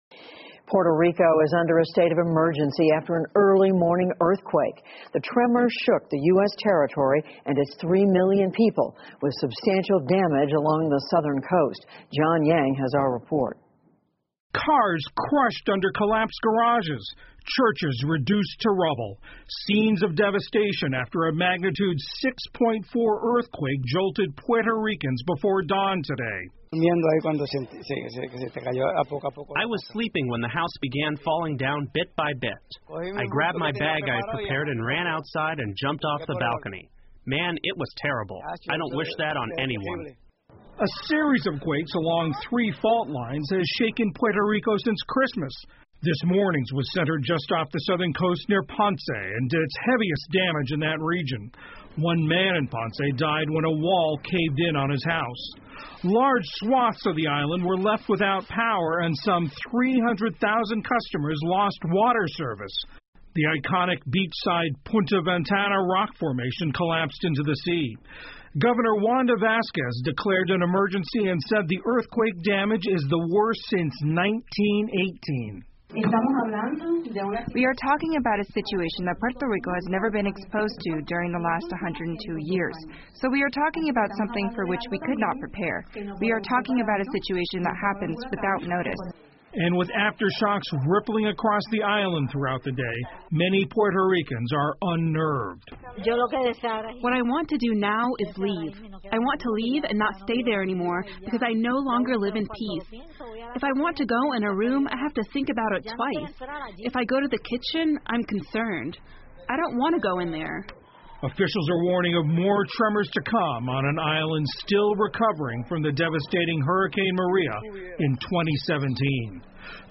PBS高端访谈:美属波多黎各发生地震 听力文件下载—在线英语听力室